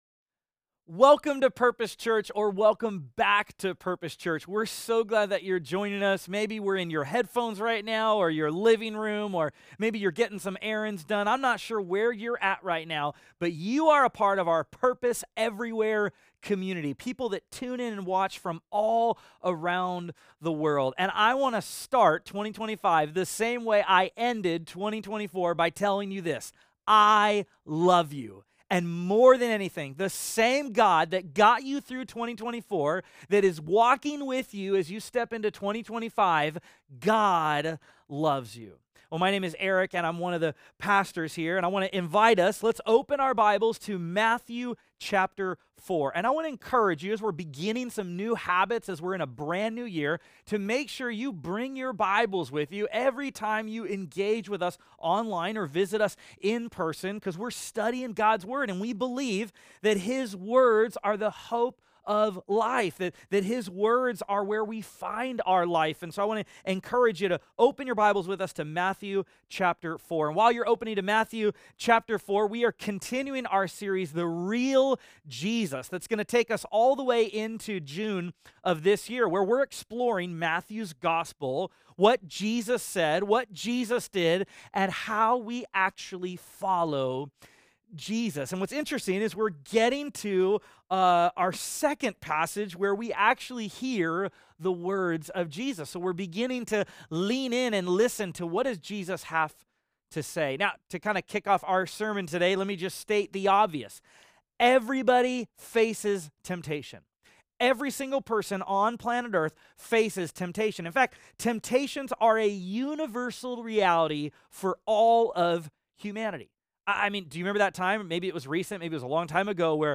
Study Guide | Download Audio File Traditional Worship (In-Person Service)